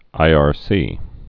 (īär-sē)